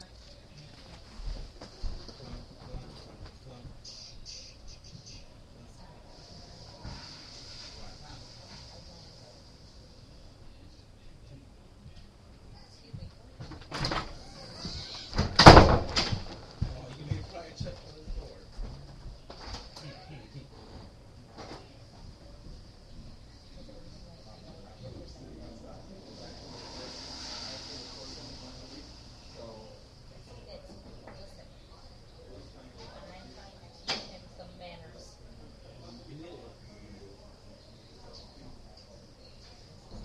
Field Recording 4
SOUND CLIP: Republic Hall Upstairs Hallway LOCATION: Republic Hall Upstairs Hallway SOUNDS HEARD: people talking, room door open, room door slam, footsteps, toilet flush, bathroom door latch shut